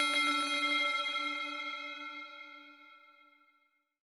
new_tones2.R.wav